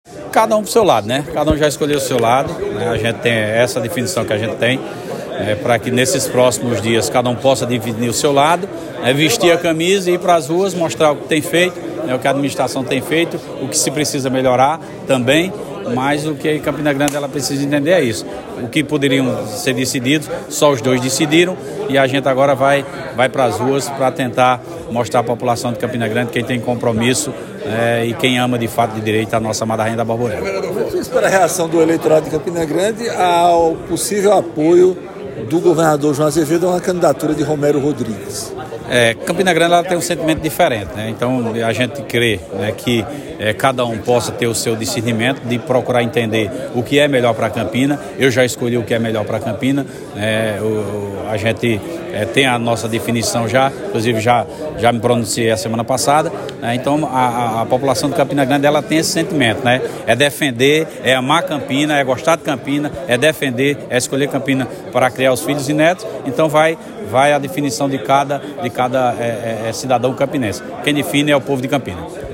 Abaixo a fala do deputado estadual Sargento Neto.